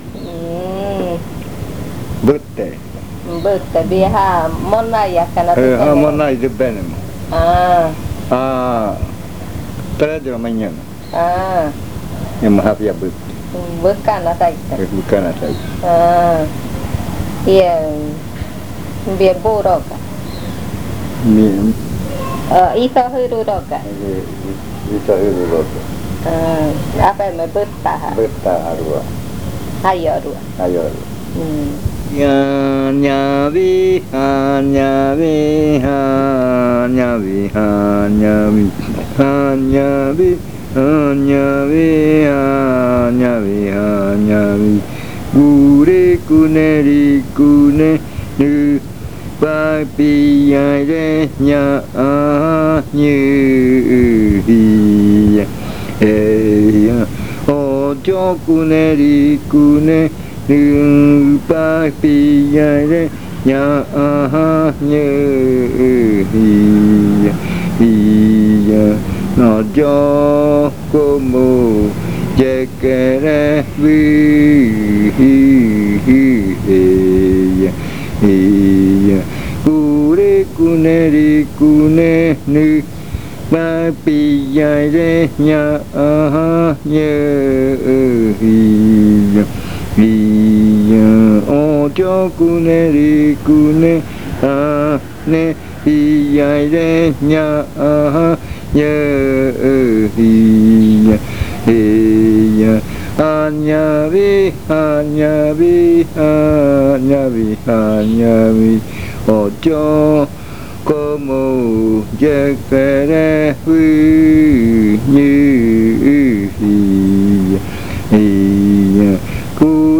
Leticia, Amazonas
Canto con adivinanza
Chant with riddle
original cassette 05B-4). This chant is part of the collection of chants from the Yuakɨ Murui-Muina (fruit ritual) of the Murui people